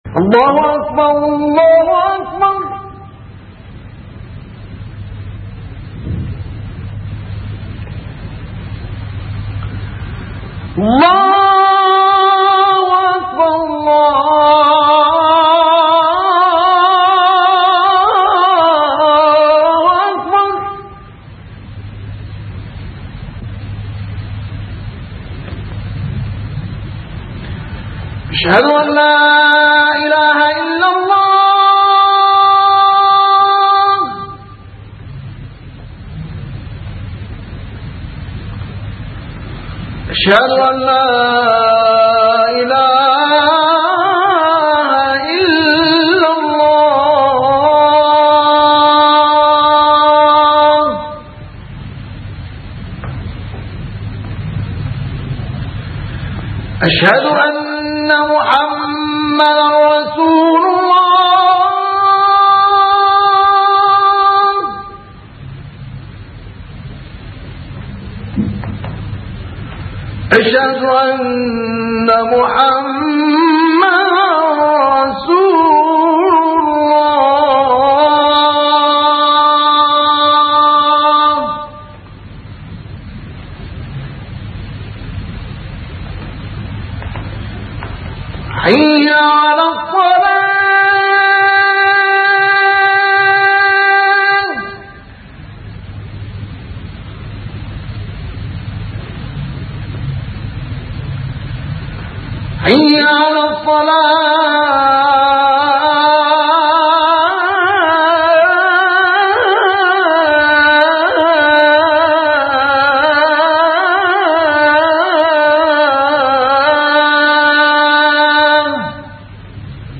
مجموعة من اجمل اصوات الاذان من جميع الدول العربية
موسوعة الأذان لأجمل الاصوات | الأذان بصوت الشيخ مصطفى إسماعيل ÇÓÊãÇÚ